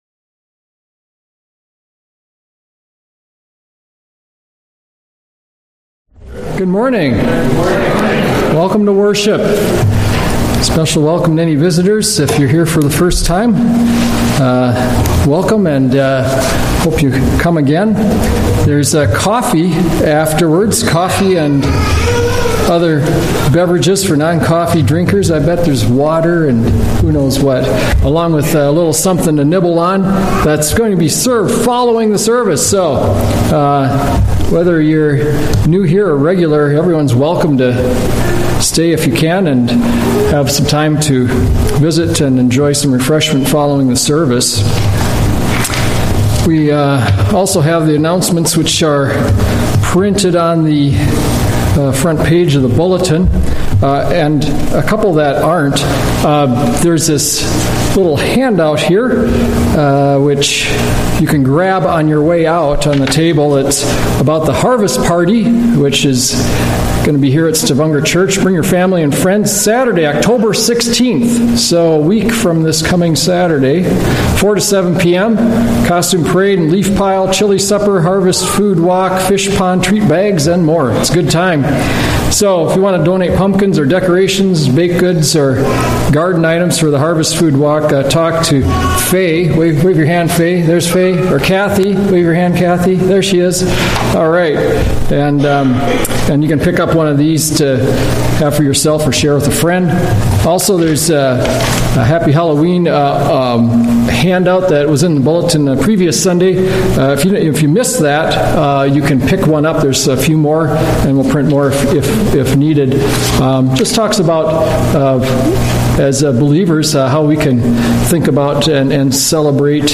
A message from the series "Sunday Worship."